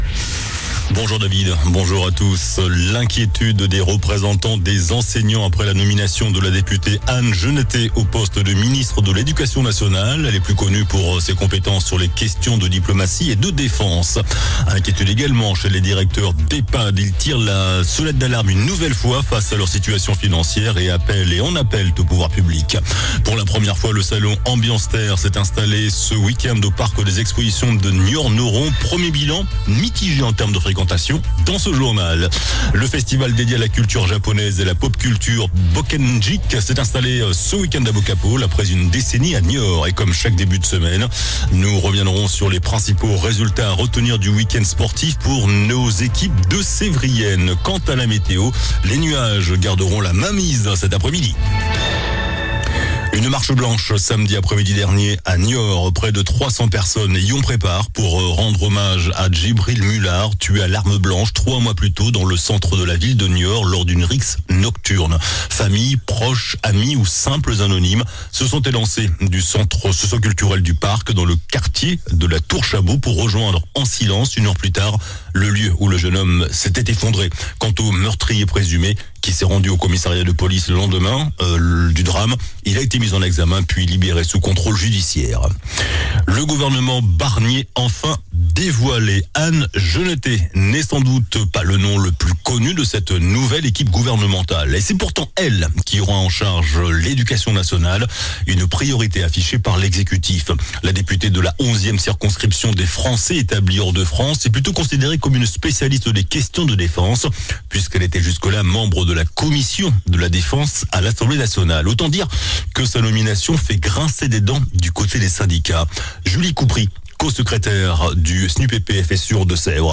JOURNAL DU LUNDI 23 SEPTEMBRE ( MIDI )